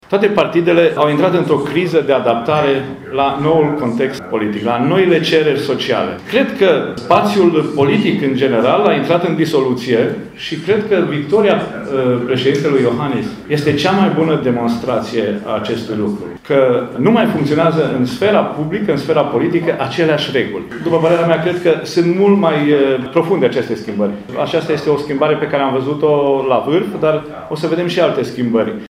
Președintele IRES, Vasile Dâncu, a declarat astăzi, la Tîrgu-Mureș, în cadrul dezbaterii despre protecția minorităților, că UDMR a intrat și ea în criza politică generală.